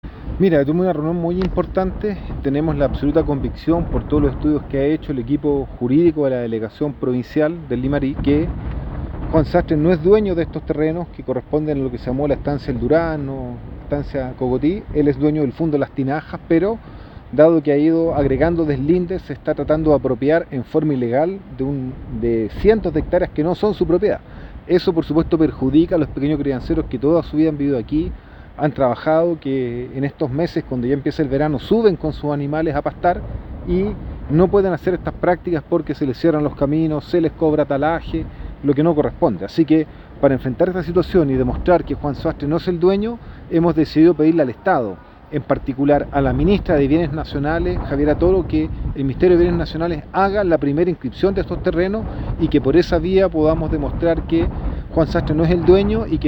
Por su parte, el senador Núñez tras una importante reunión sostenida en el lugar con los afectados, explicó que
Senadornunez_lainvernada.mp3